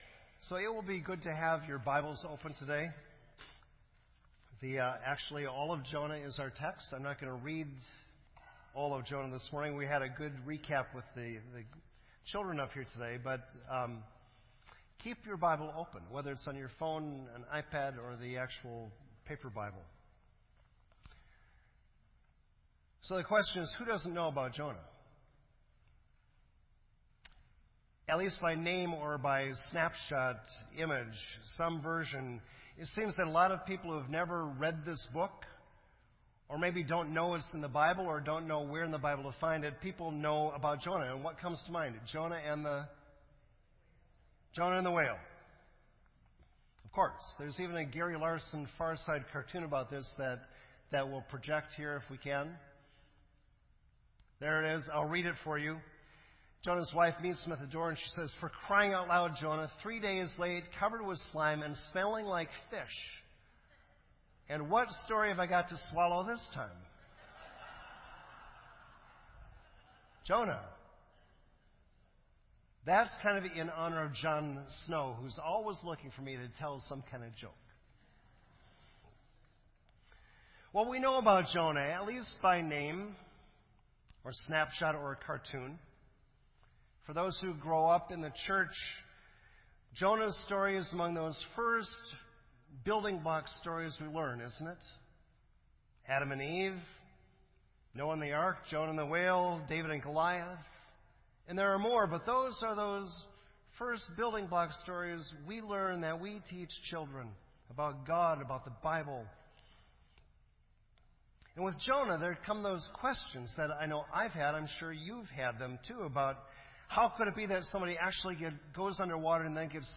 This entry was posted in Sermon Audio on July 10